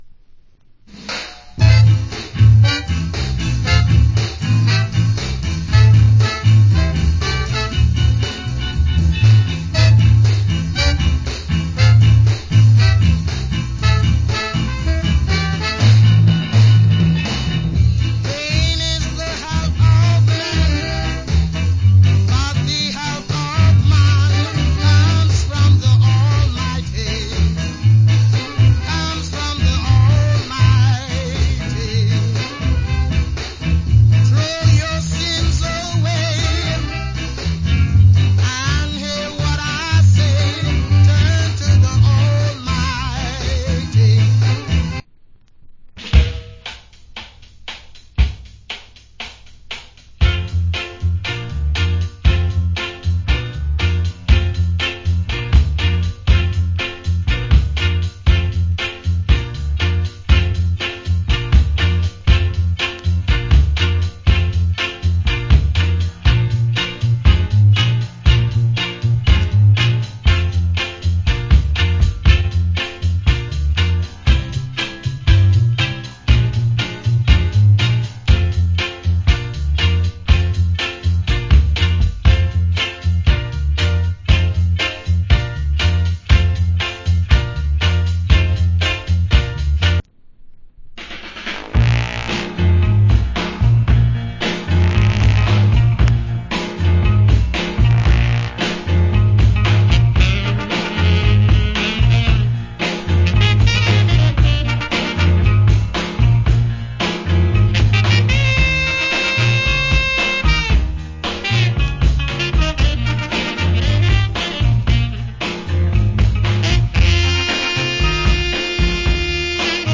Great Ska Vocal & Inst Double. 2003.